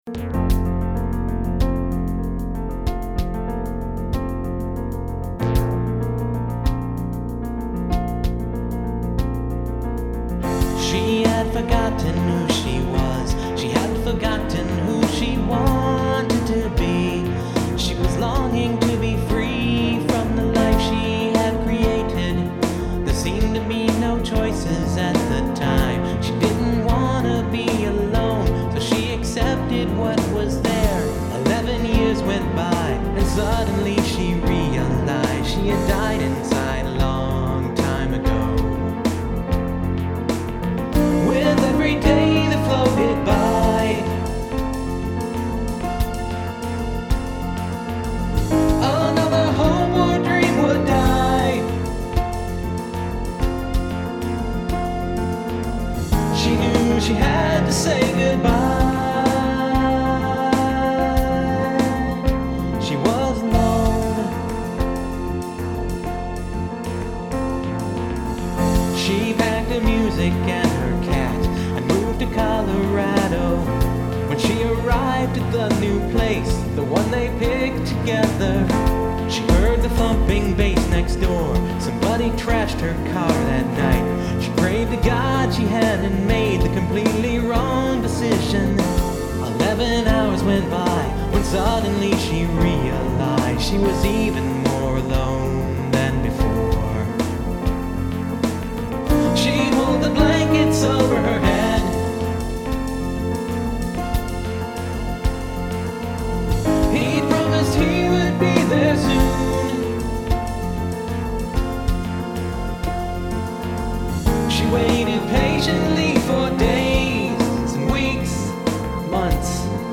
I could never find the correct solo for the ending. I tried about 5 different things, piano, strings, synth... never happy with any of them.